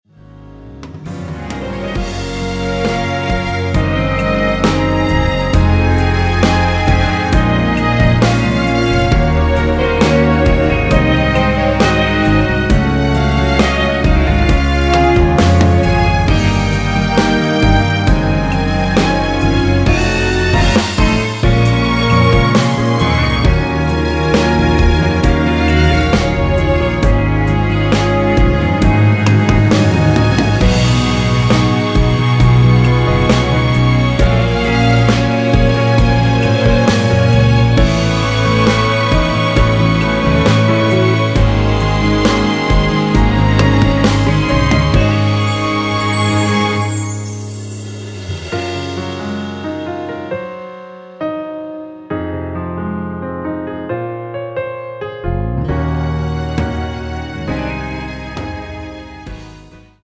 원키에서(-2)내린 MR입니다.
Db
앞부분30초, 뒷부분30초씩 편집해서 올려 드리고 있습니다.